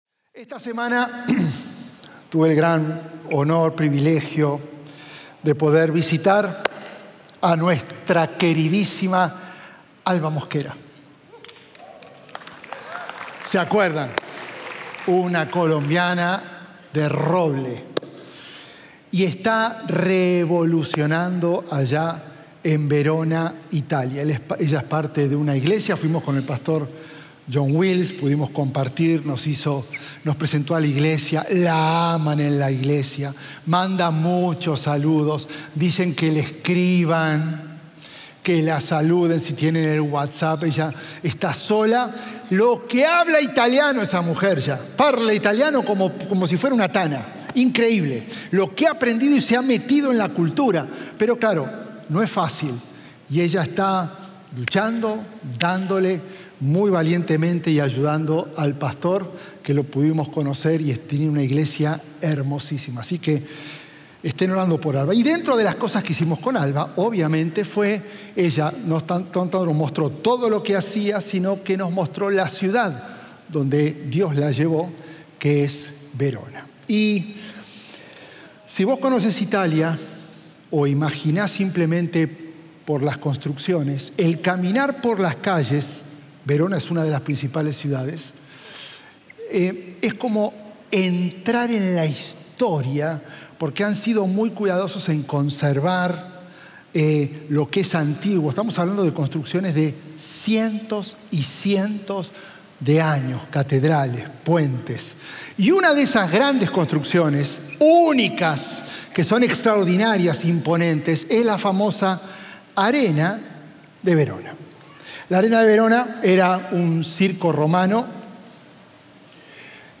Sermones Dominicales – Media Player